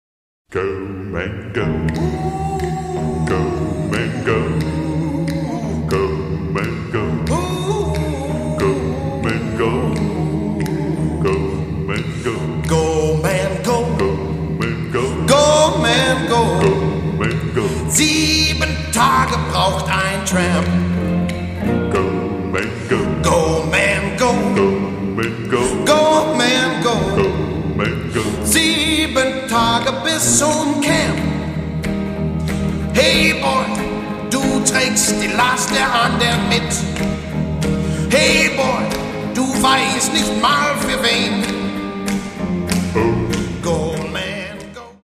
Aufgenommen 1958 - 1962